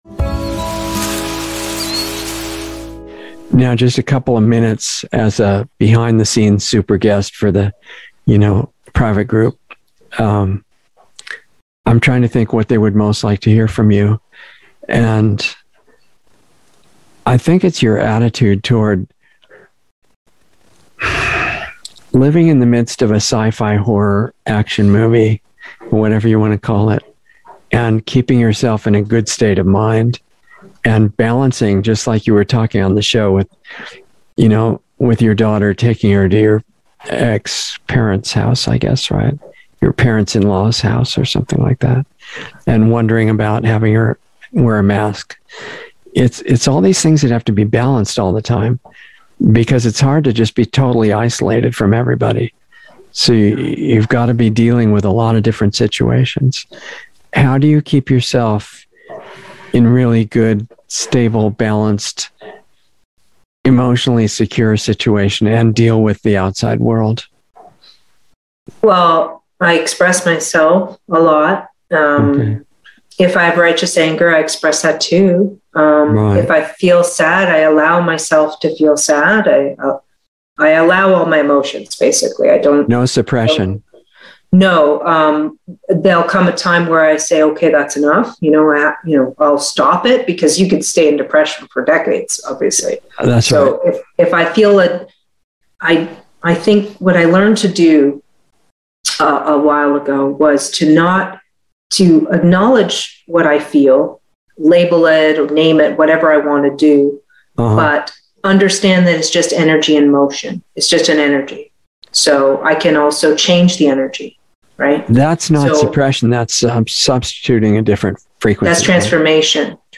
Insider Interview 1/13/22